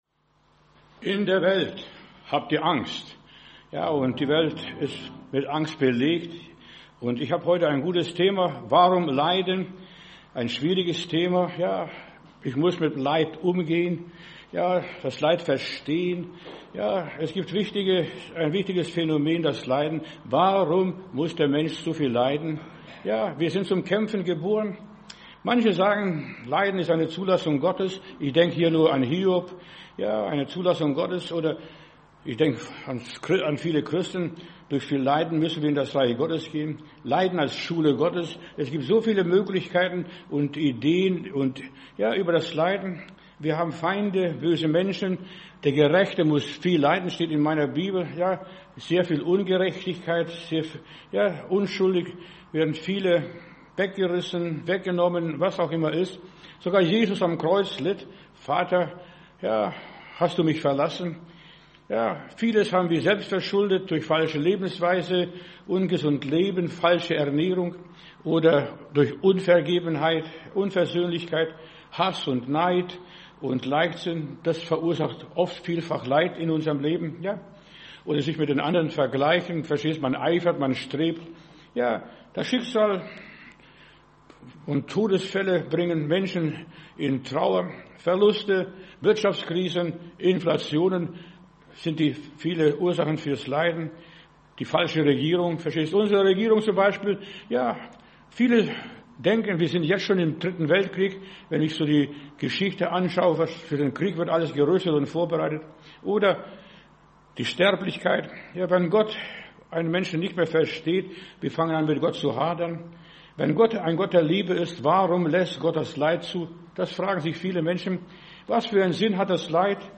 Predigt herunterladen: Audio 2026-02-25 Warum Leiden?